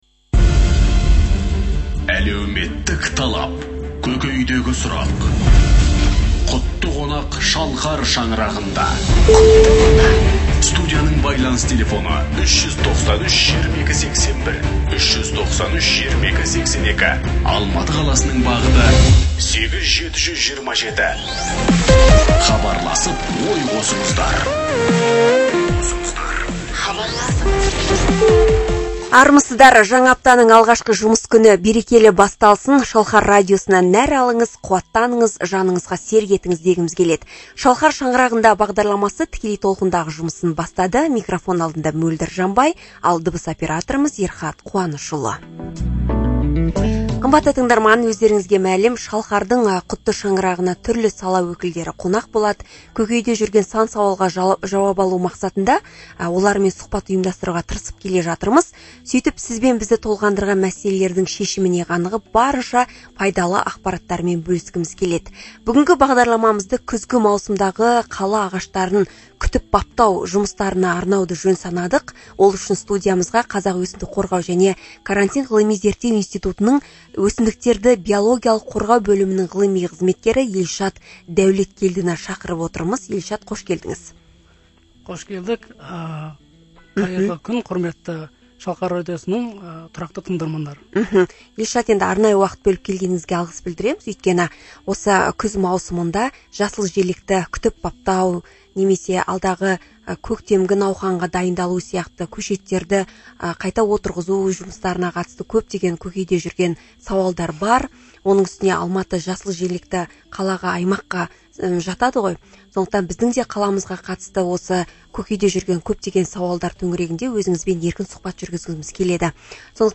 Запись прямого эфира радио "Шалкар"